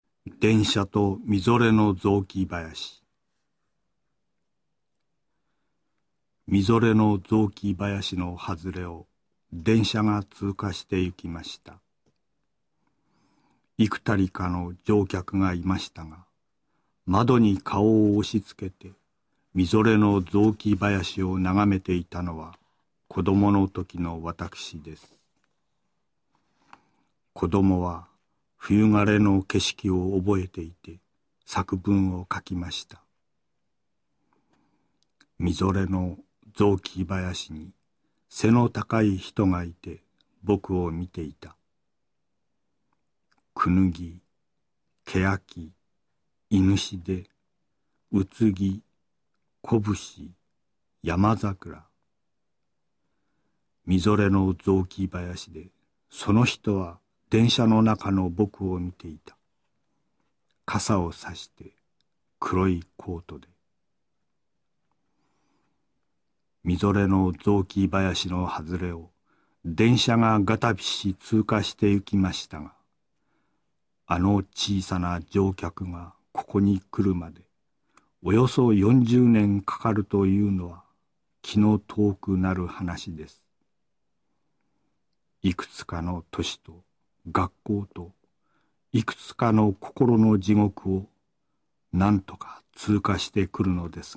辻征夫の「電車と霙の雑木林」を読む